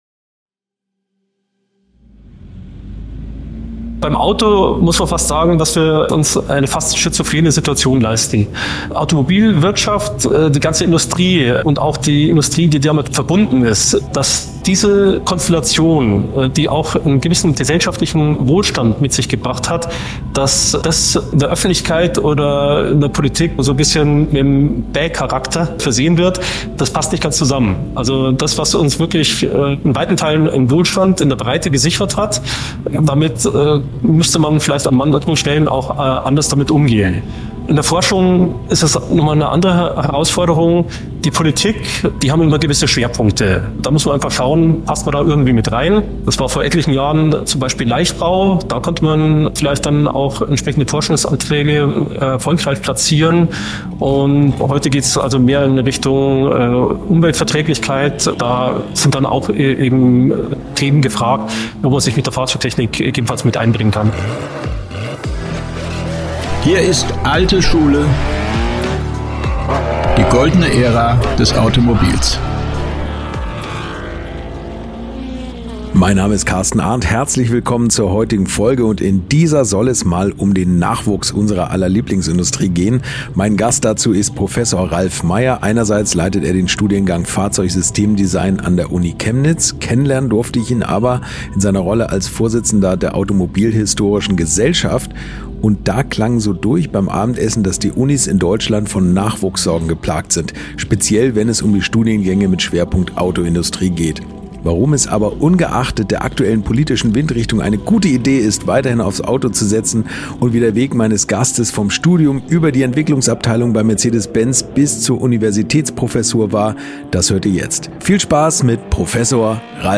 Die Interviewreihe mit Menschen, die die Welt des Automobils geprägt haben. Ob Rennfahrer, Entwickler, Designer, Sammler oder Tuner – es gibt unzählige Persönlichkeiten, die verrückte Geschichten erlebt und Beeindruckendes erreicht haben.